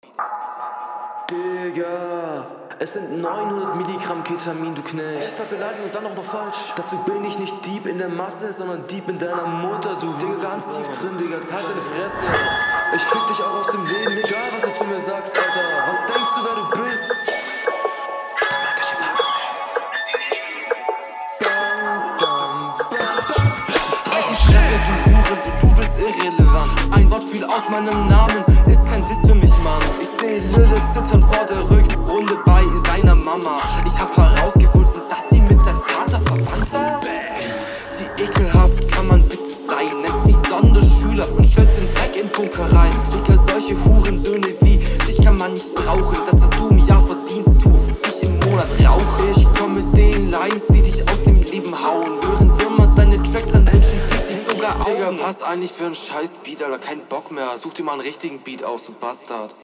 is jetzt nich so der move bisschen langweilig aufm gegnerbeat zu rappen und dann zu …